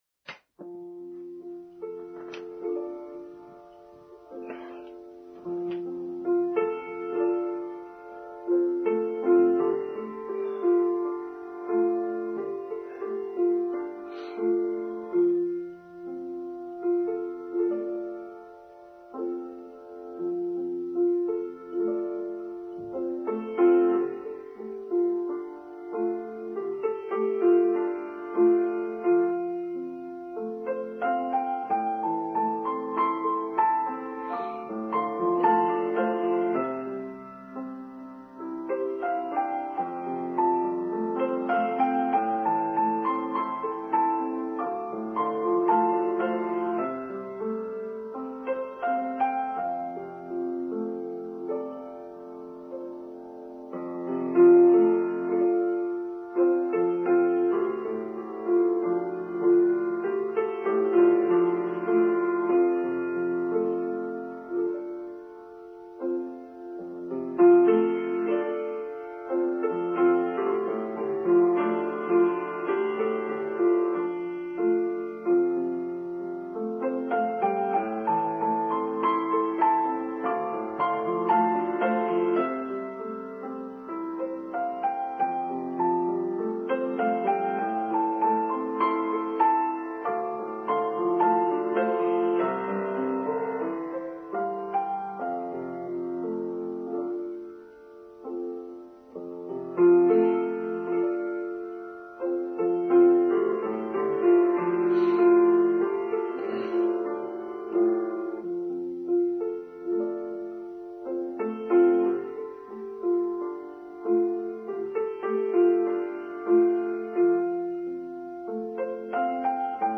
Two Steps Forward, One Step Back: Online Service for Sunday 5th September 2021